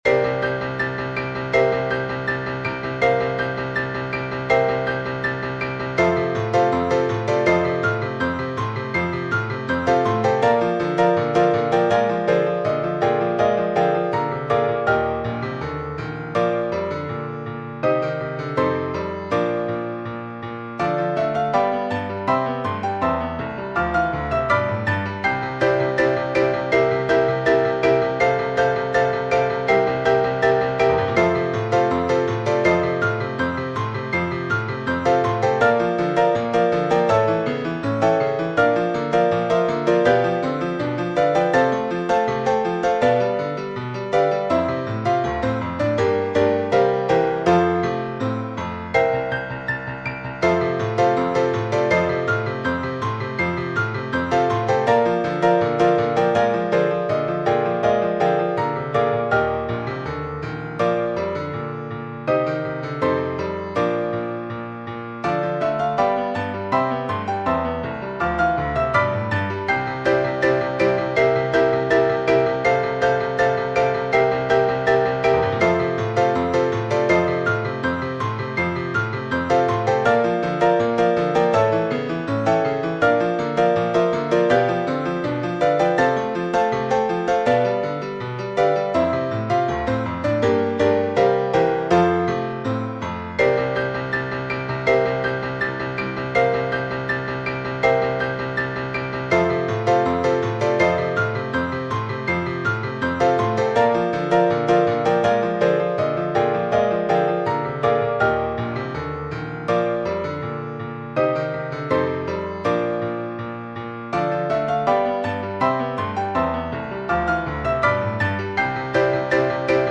This piano duet is my attempt at another game show song
fanfare